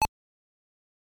option_change.ogg